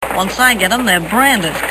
Tags: Mae West Mae West movie clips Come up and see me some time Mae West sound Movie star